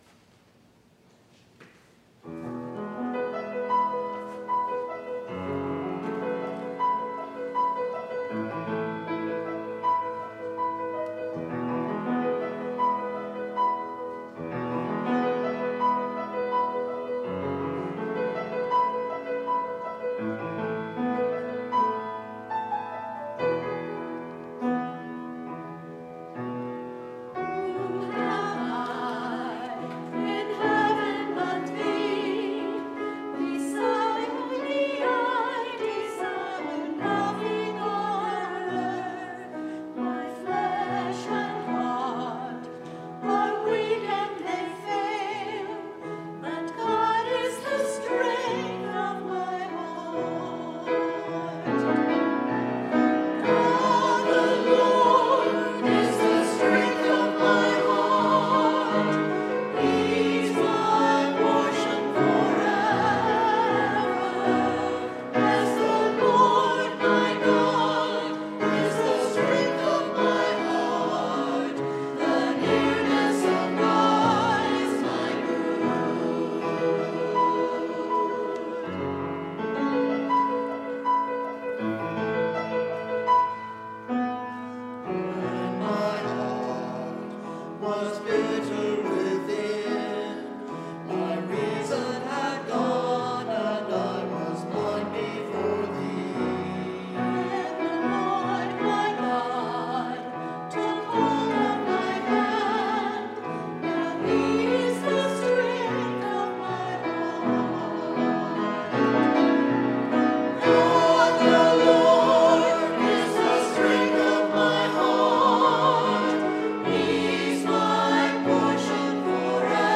anthem_9_29_19.mp3